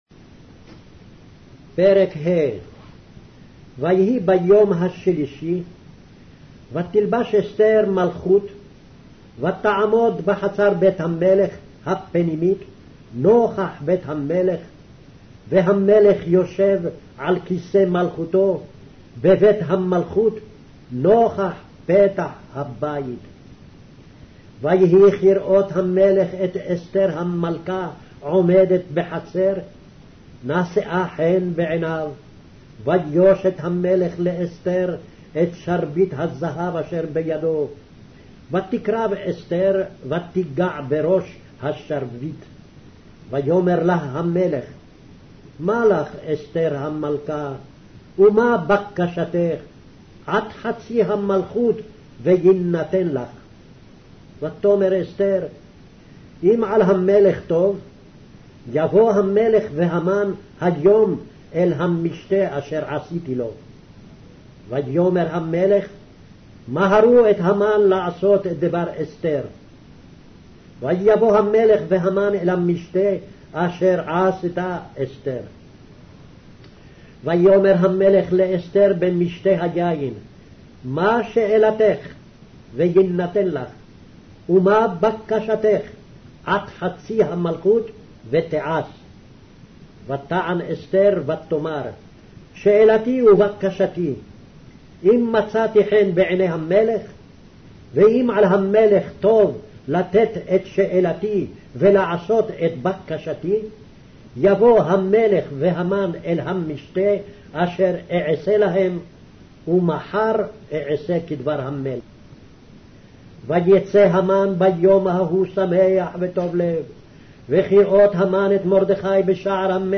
Hebrew Audio Bible - Esther 3 in Mrv bible version